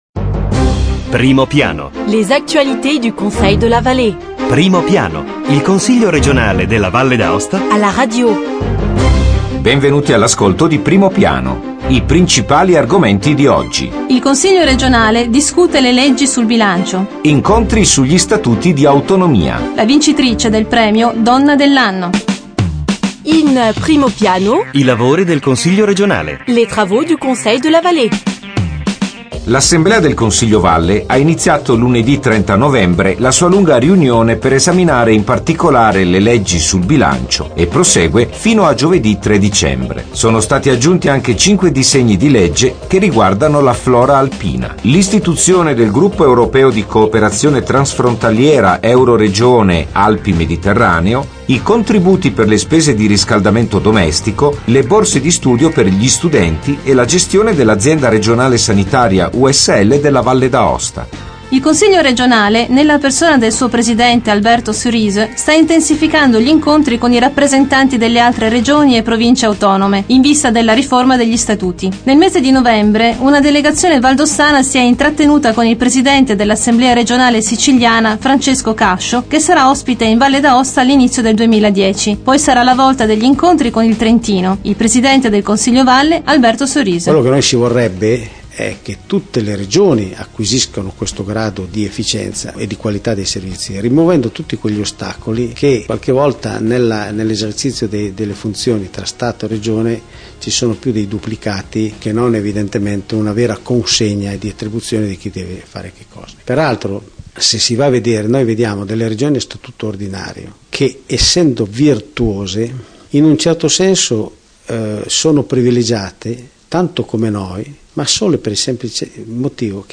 Evénements et anniversaires Documents liés De 1er décembre 2009 à 8 décembre 2009 Primo piano Le Conseil r�gional � la radio: approfondissement hebdomadaire sur l'activit� politique, institutionnelle et culturelle de l'assembl�e l�gislative.
Interview avec le pr�sident du Conseil de la Vall�e, Alberto Cerise; - Le Prix international "La femme de l'ann�e" a �t� attribu�.